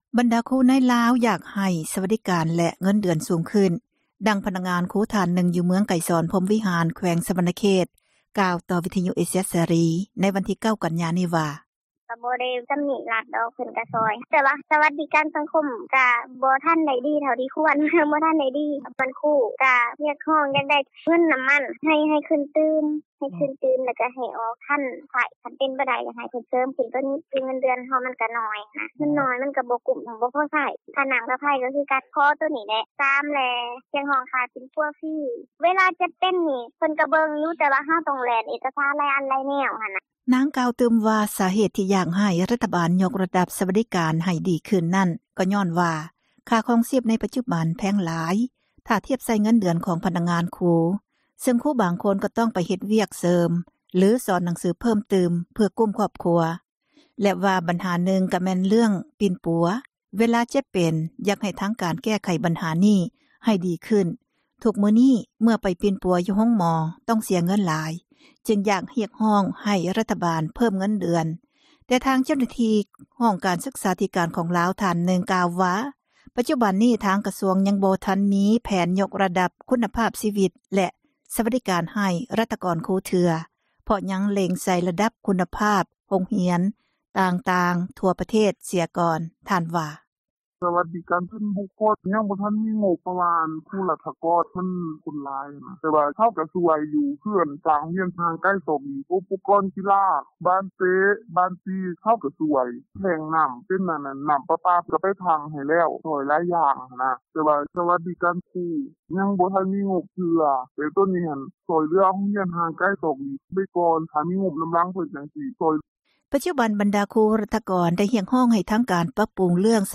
ບັນດາຄຣູໃນລາວ ຢາກຮຽກຮ້ອງ ສວັດດີການ ແລະ ເງິນເດືອນສູງ, ດັ່ງພະນັກງານຄຣູ ທ່ານນຶ່ງ ຢູ່ເມືອງໄກສອນ ພົມວິຫານ ແຂວງ ສວັນນະເຂດ ກ່າວຕໍ່ ວິທຍຸ ເອເຊັຽ ເສຣີ ໃນວັນທີ 9 ກັນຍາ ນີ້ວ່າ: